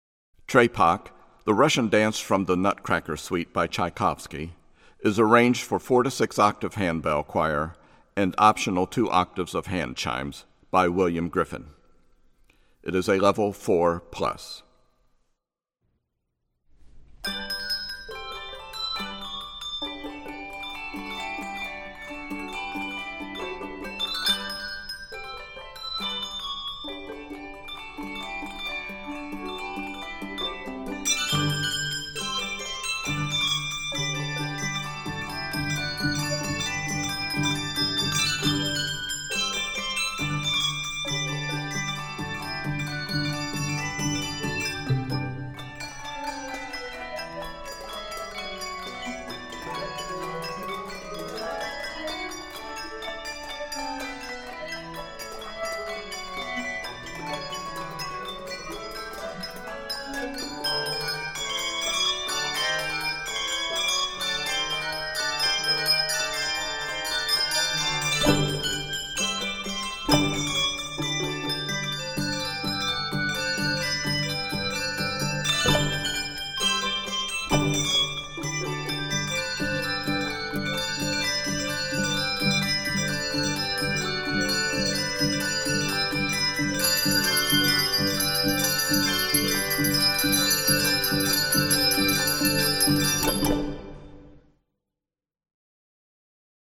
Key of G Major.
Octaves: 4-6